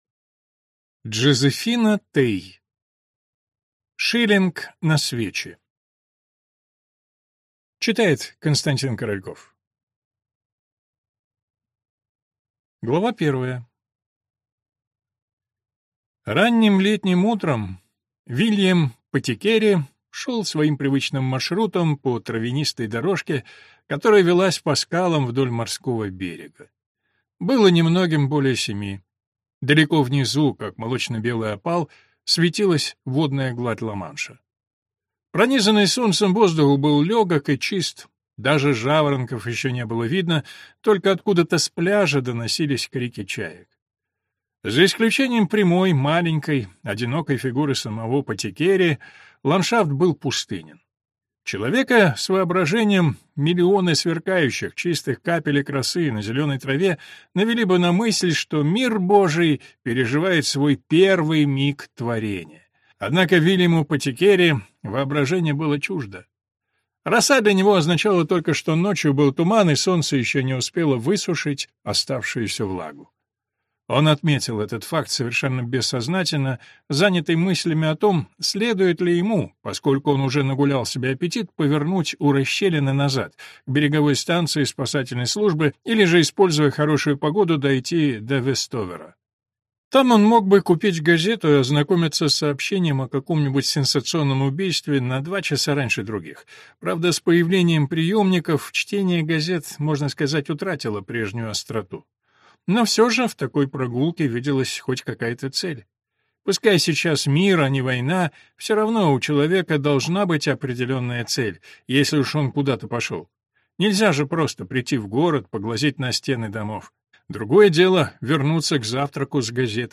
Аудиокнига Шиллинг на свечи | Библиотека аудиокниг